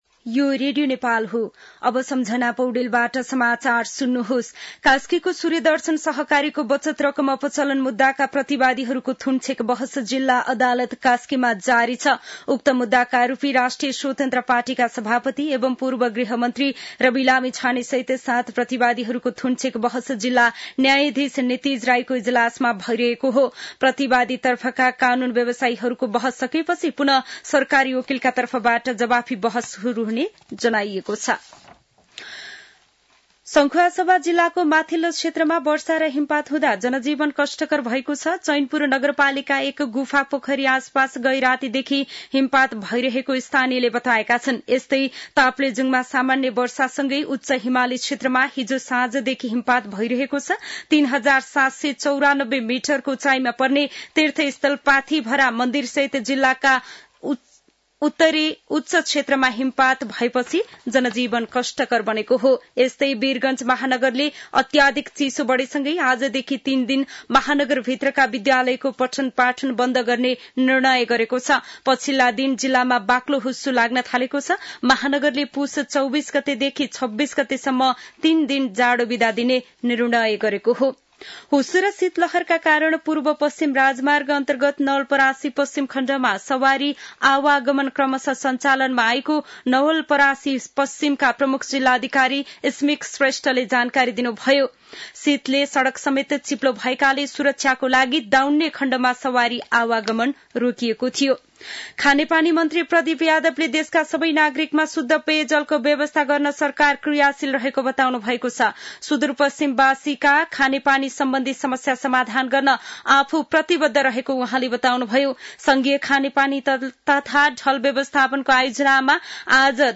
दिउँसो १ बजेको नेपाली समाचार : २५ पुष , २०८१